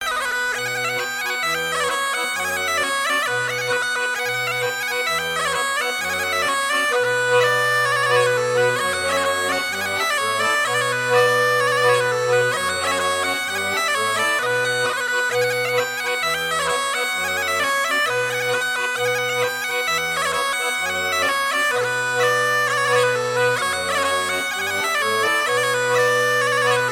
Couplets à danser
danse : branle : courante, maraîchine
Pièce musicale éditée